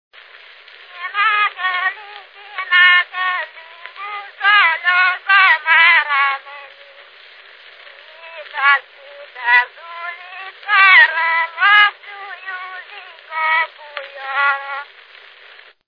Moldva és Bukovina - Moldva - Klézse
Stílus: 7. Régies kisambitusú dallamok
Kadencia: b3 (1) 4 1